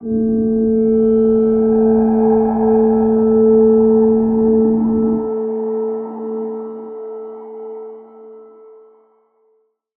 G_Crystal-A4-pp.wav